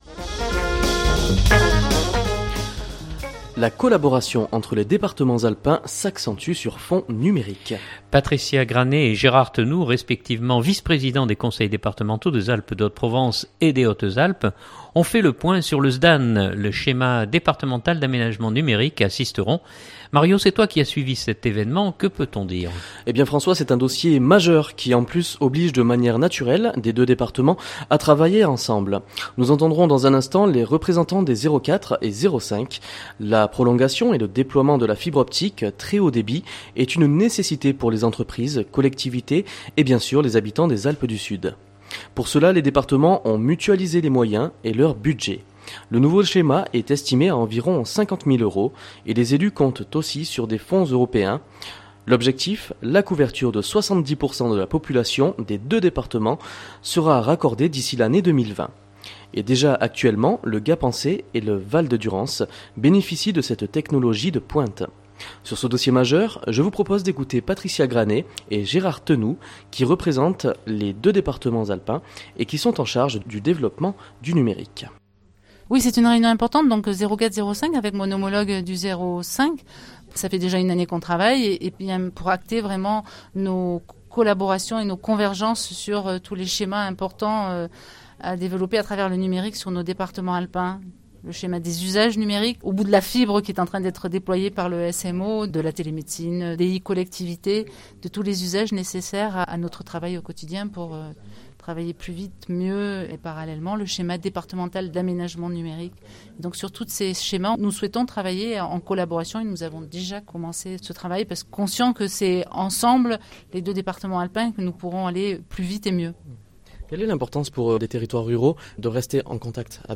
Sur ce dossier majeur, Fréquence Mistral vous propose d’écouter Patricia Granet et Gérard Tenoux qui représentent les deux départements alpins et qui sont en charge du développement du numérique.